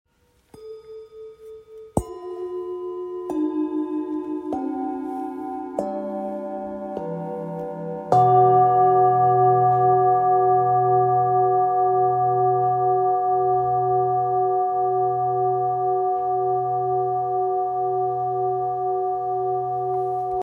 7 Chakra Sound Bowl Set – Handcrafted
Made from a special blend of metals and engraved with intricate designs, this set includes seven bowls, each tuned to the unique frequency of a specific chakra.
• Seven bowls, each resonating with a different chakra frequency
Let the soothing sound of these bowls guide you toward inner peace, clarity, and balance.
7-bowl-2.mp3